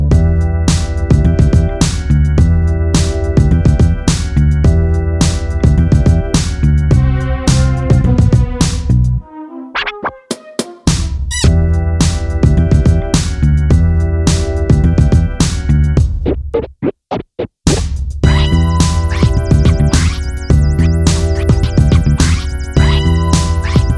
no Backing Vocals or Vocoder R'n'B / Hip Hop 3:28 Buy £1.50